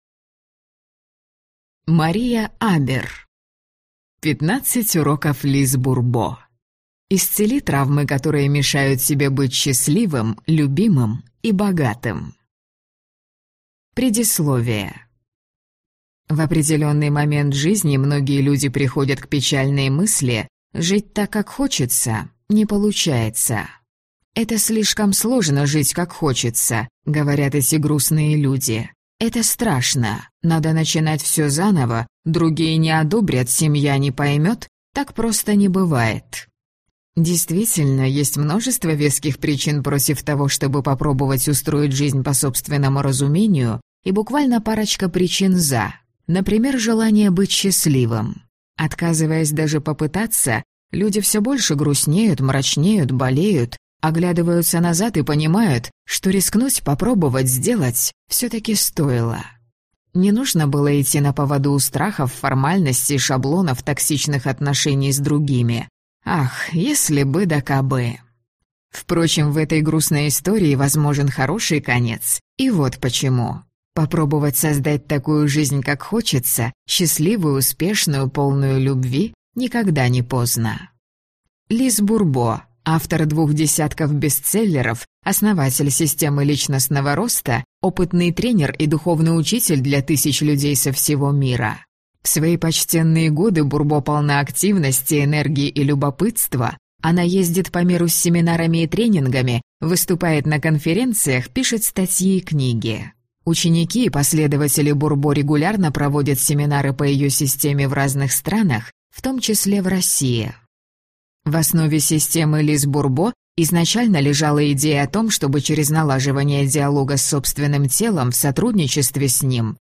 Аудиокнига 15 уроков Лиз Бурбо. Исцели травмы, которые мешают тебе быть счастливым, любимым и богатым | Библиотека аудиокниг